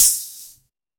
Sizzle